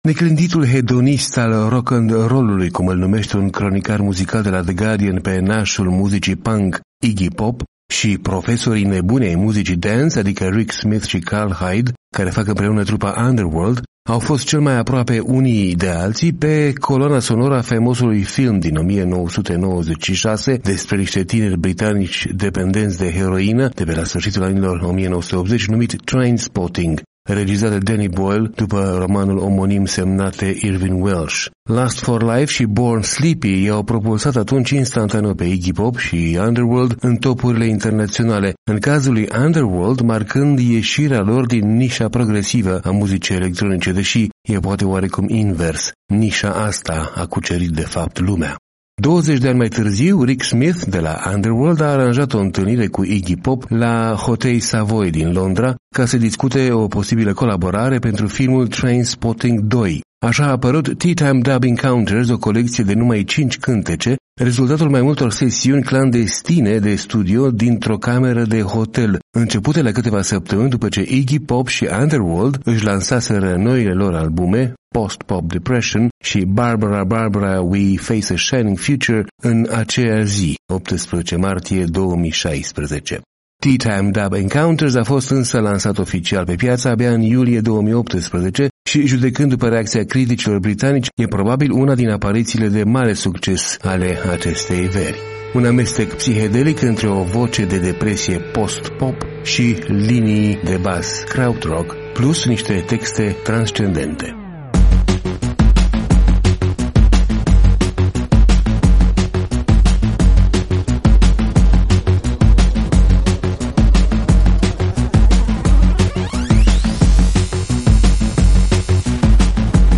Un amestec psihedelic între o voce de „depresie post-pop” și linii de bass kraut-rock, plus texte transcendente.